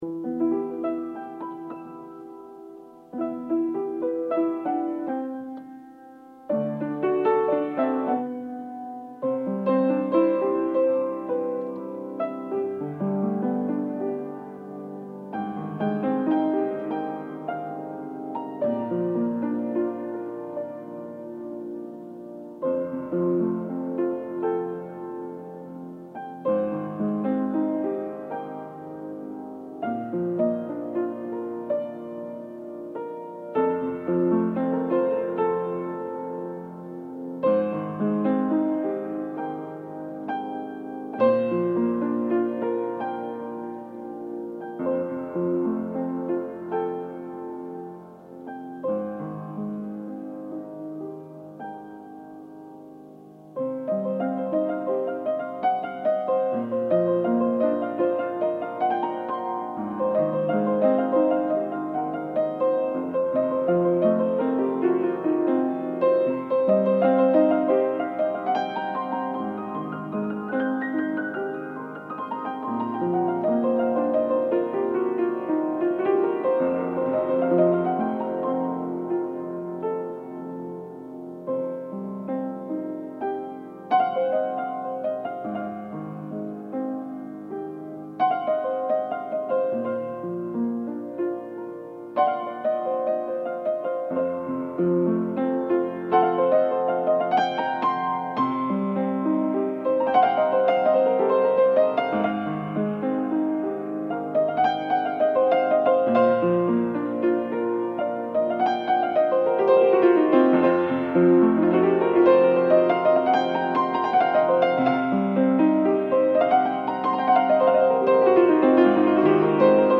Musiques jou� au piano